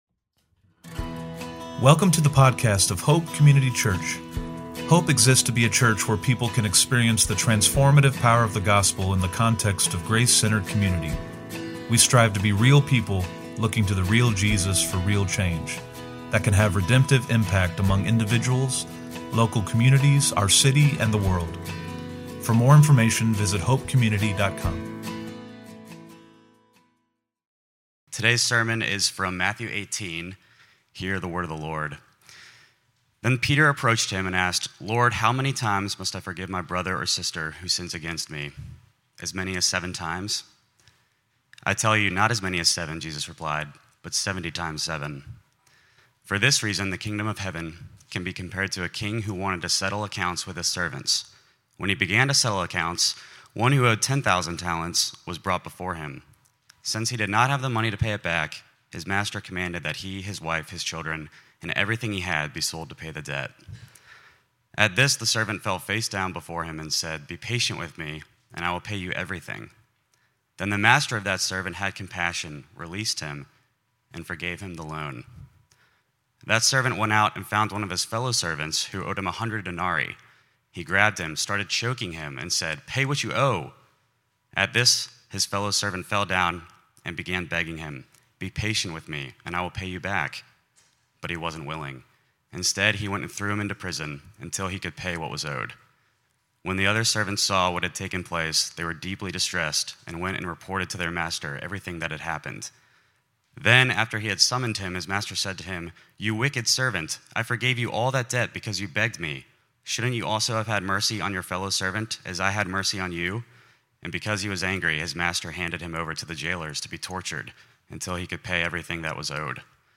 SE-Sermon-5.11.25.mp3